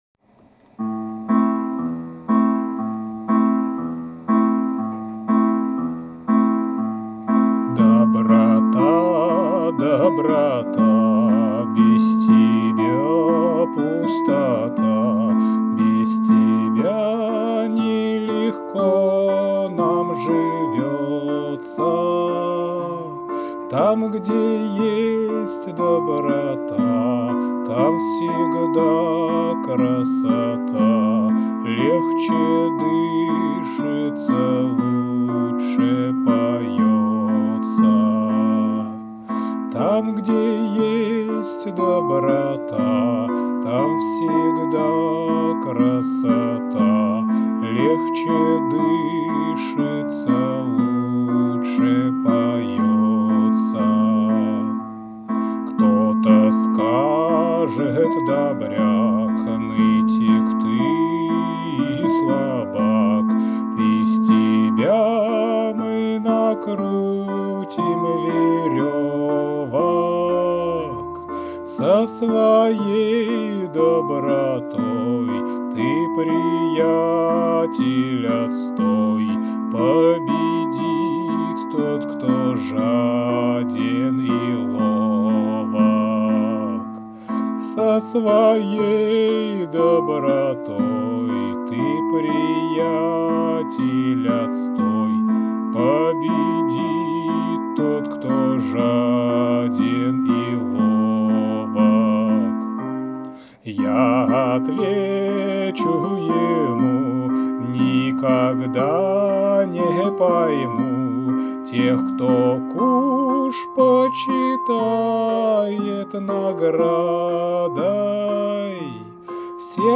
• Жанр: Детская